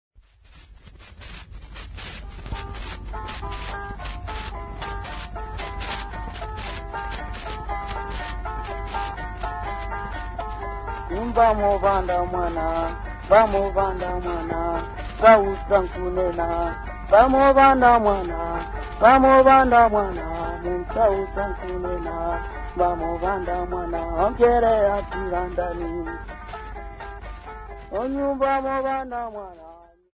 Folk Music
Field recordings
Africa Malawi city not specified f-mw
Indigenous music